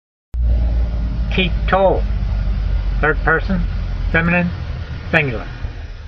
My reading (voice) in modern Israeli style is only good enough to get you started.
teek-tol